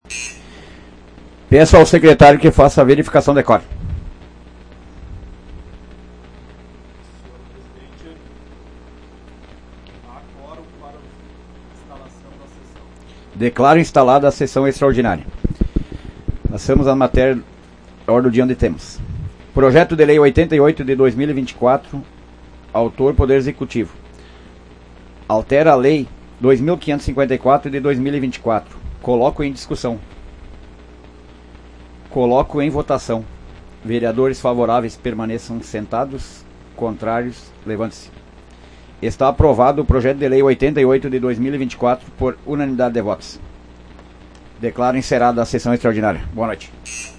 Áudio da 95ª Sessão Plenária Extraordinária da 16ª Legislatura, de 14 de outubro de 2024